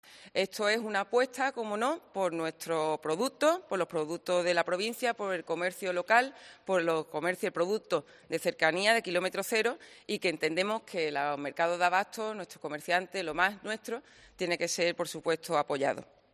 AUDIO: La presidenta de la Diputación de Cádiz, Almudena Martínez, en la presentación de Cádiz Vale +